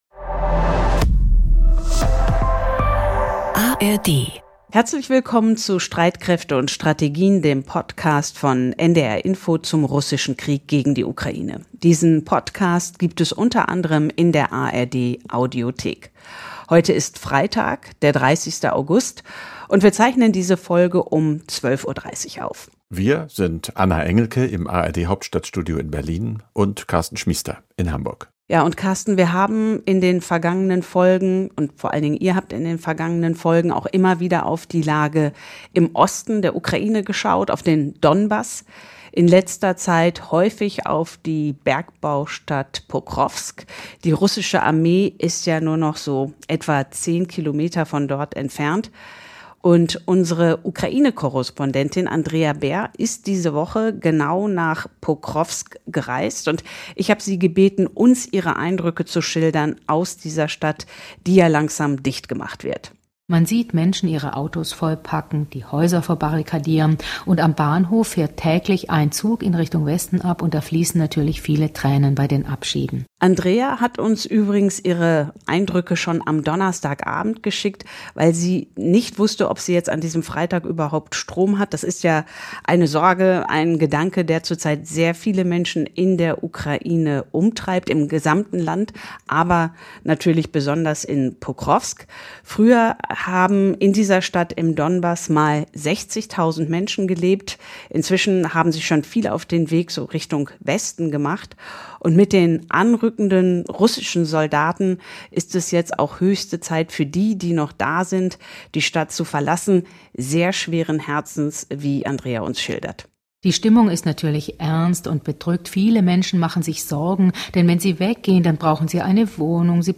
Interview mit Botschafter Oleksii Makeiev